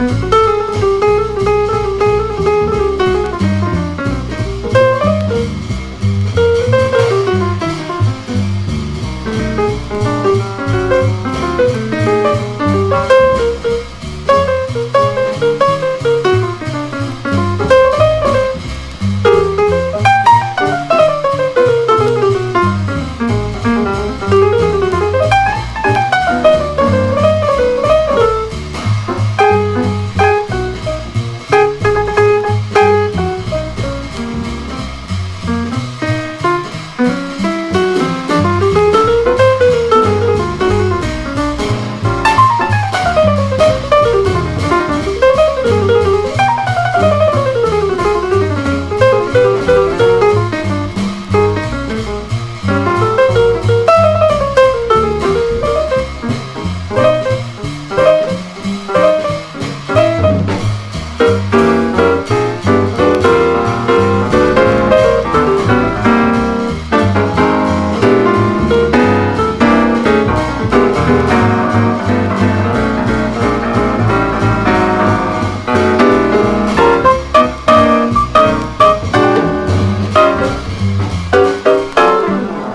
全編でコーラスをフィーチャーしたスピリチュアル・ジャズ・クラシック！